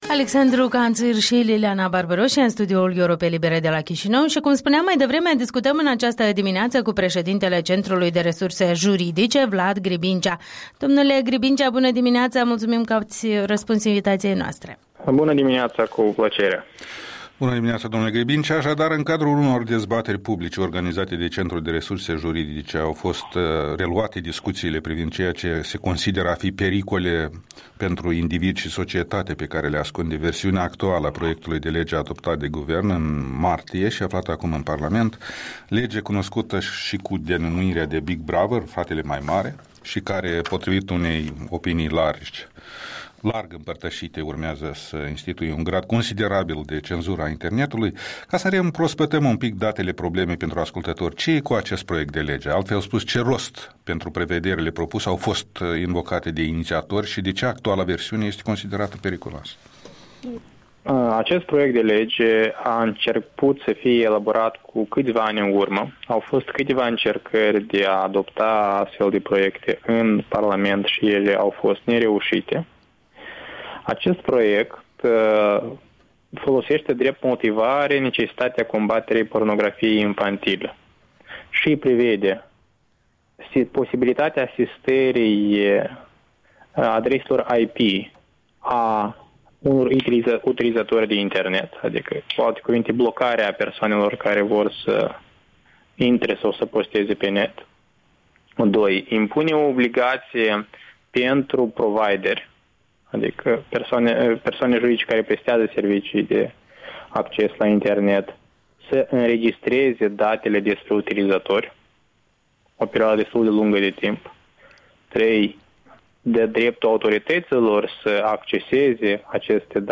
Interviul dimineții